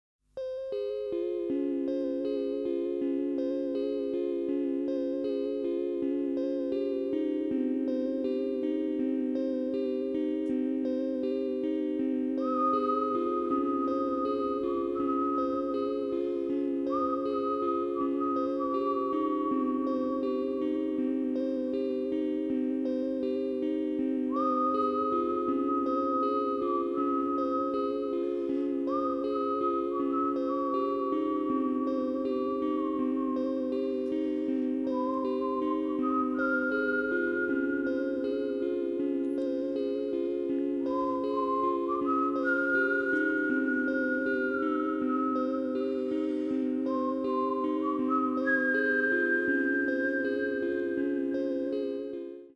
Respiration musicale :